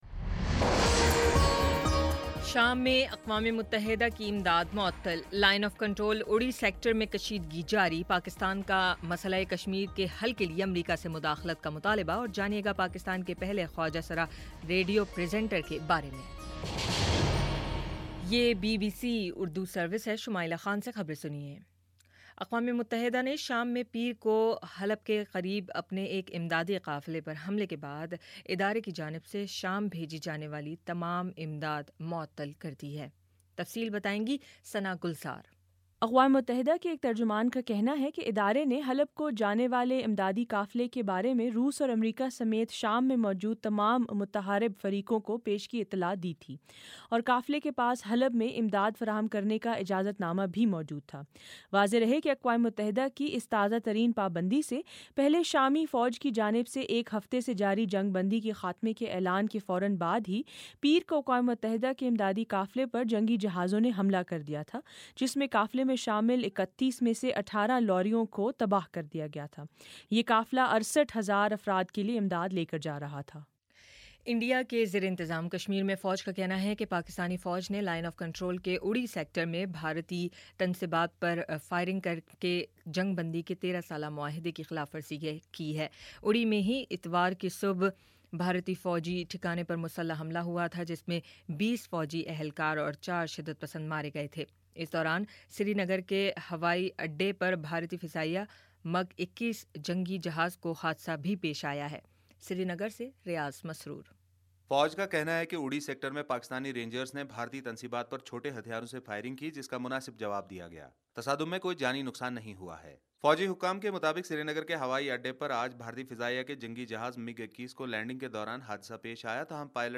ستمبر 20 : شام پانچ بجے کا نیوز بُلیٹن